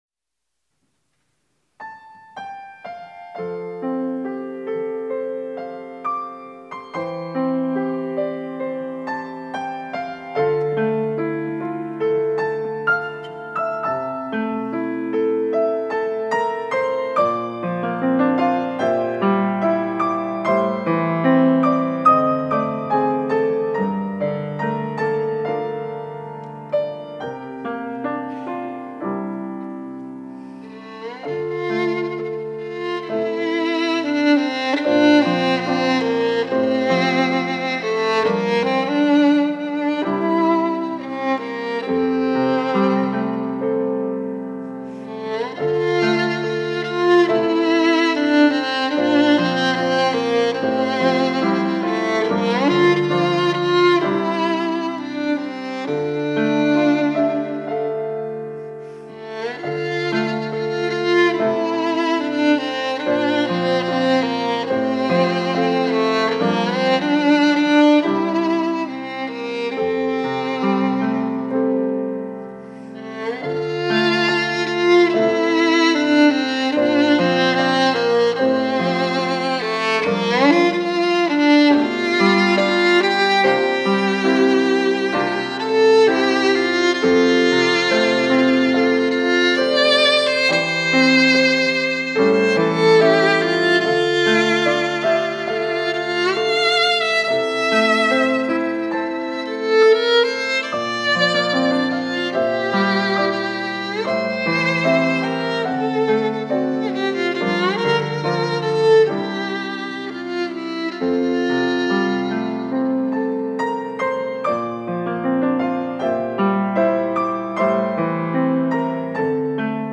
특송과 특주 - 복음밖에 없습니다